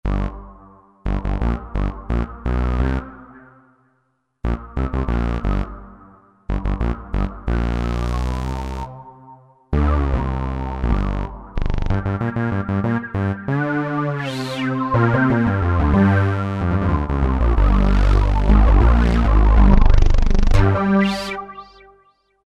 163 Juno X Bizarre Juno rendition